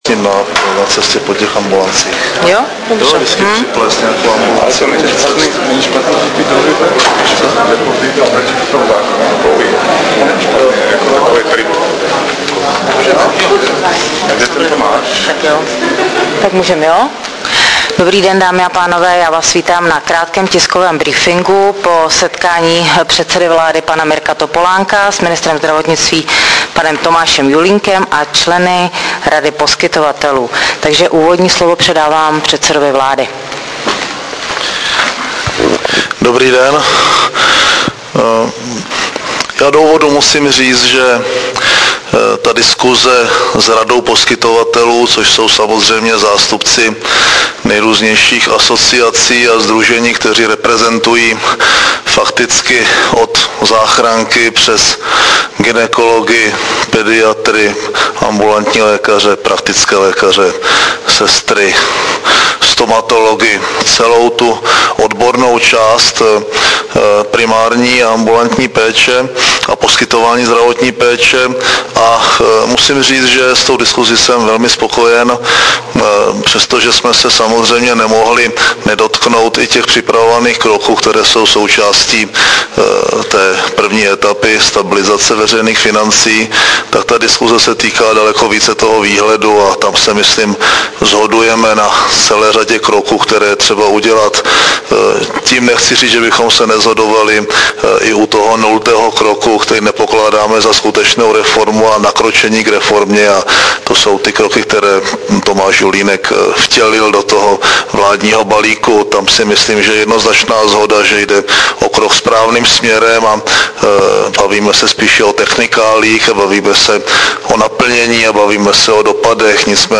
Tisková konference premiéra Mirka Topolánka po jednání s ministrem Tomášem Julínkem a členy Rady poskytovatelů 29.5.2007